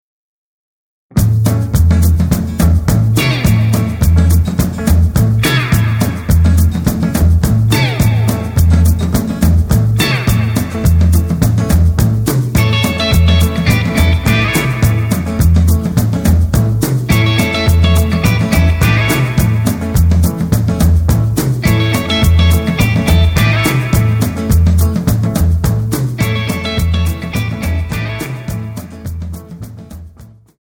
Tonart:A ohne Chor